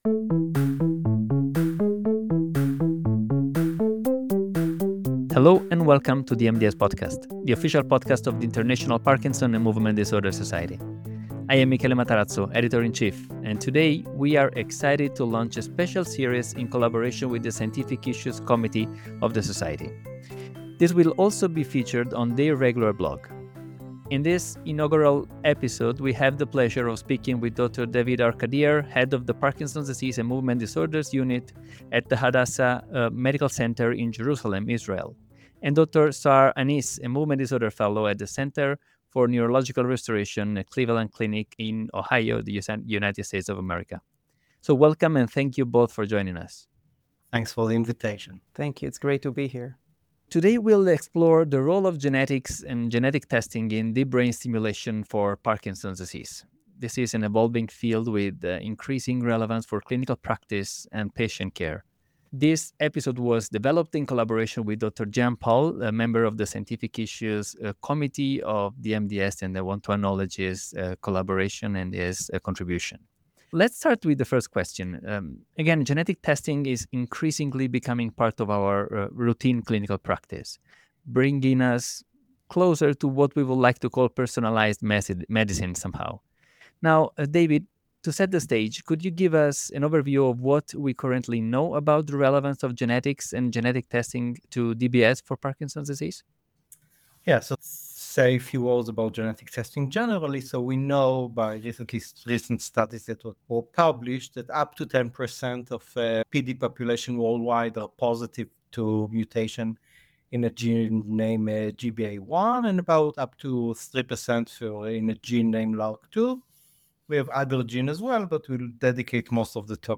This interview is also available in the text format within the MDS Scientific Issues: Read the issue